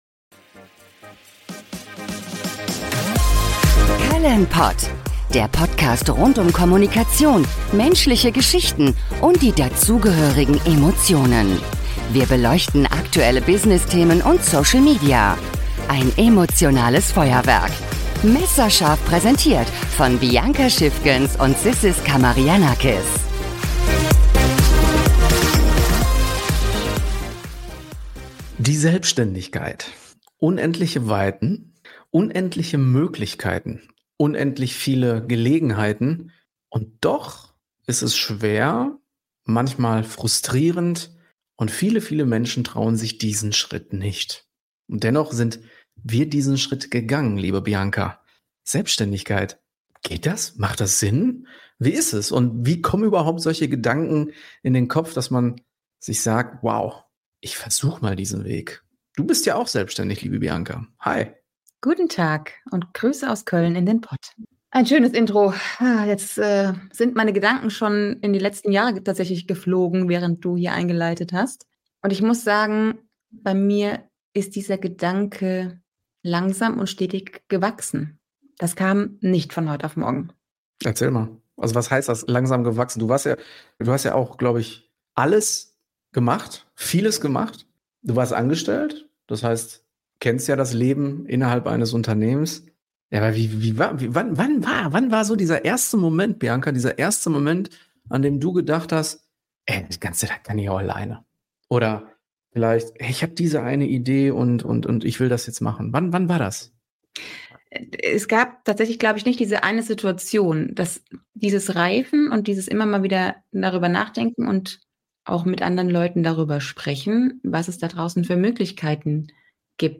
Unsere ausdrucksstarken Hosts teilen ihre persönlichen Geschichten und entschlüsseln, was es wirklich heißt, als Selbstständige durchzustarten. Außerdem konfrontieren sie die Hörer:innen mit der nackten Wahrheit, unterlegt von ihrer typischen Mischung aus Leidenschaft, Humor und einer Prise Zynismus. Sie sprechen über die Herausforderungen und Triumphe der Selbstständigkeit, bieten Einblicke in ihre eigene Reise als Unternehmerinnen und diskutieren die Frage, ob wirklich jeder selbstständig sein kann.